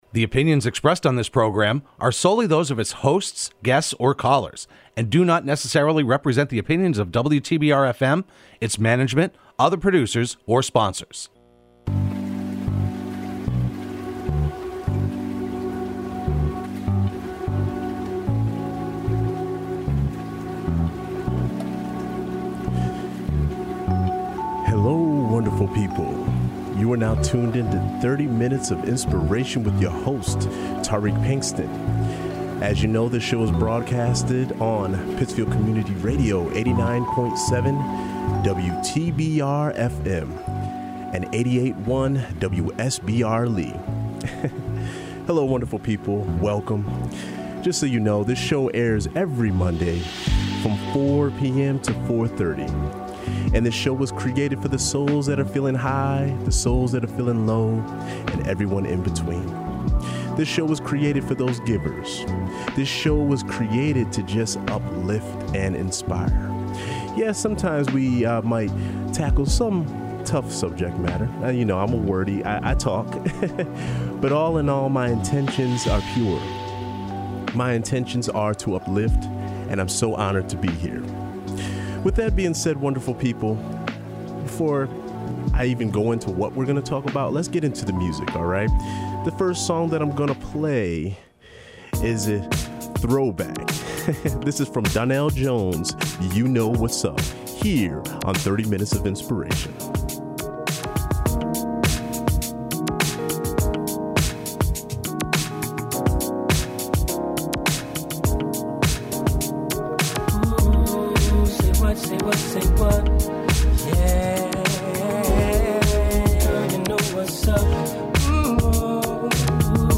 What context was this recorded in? broadcast live every Monday afternoon at 4pm on WTBR.